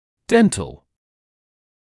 [‘dentl][‘dentl]зубной; стоматологический